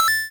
snd_feisty_mooch_coin_grab.wav